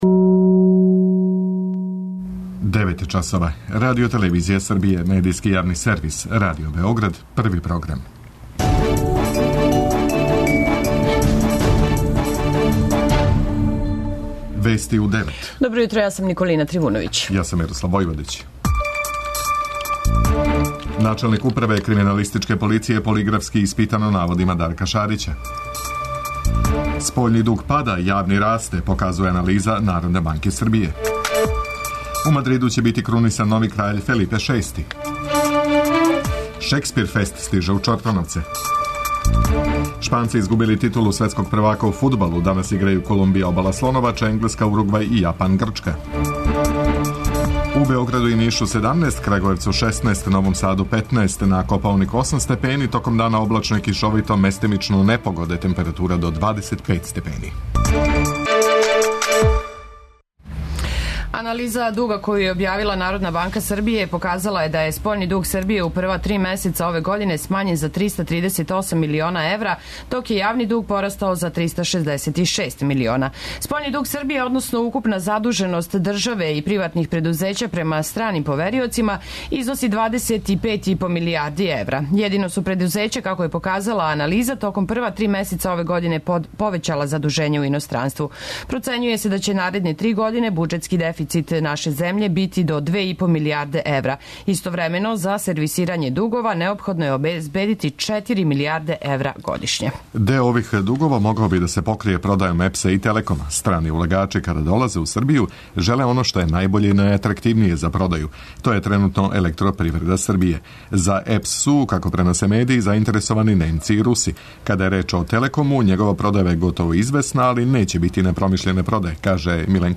преузми : 10.31 MB Вести у 9 Autor: разни аутори Преглед најважнијиx информација из земље из света.